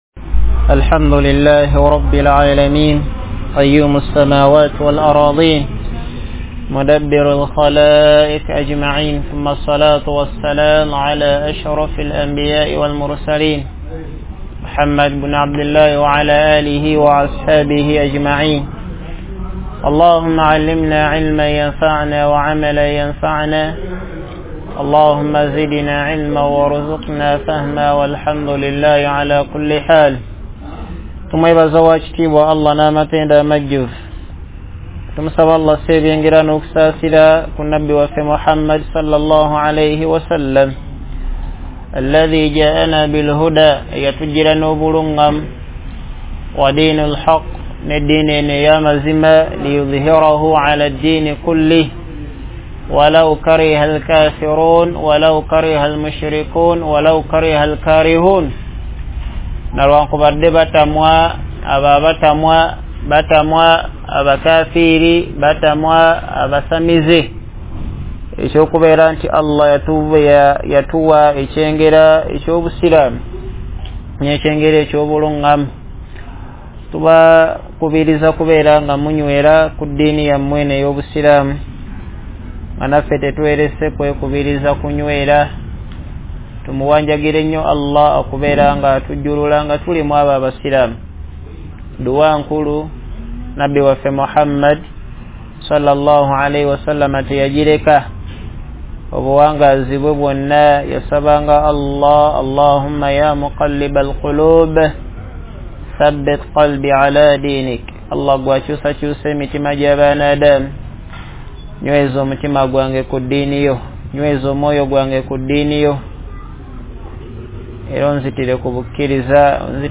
Lectures Serie : Okusala KuSwalah Mulugendo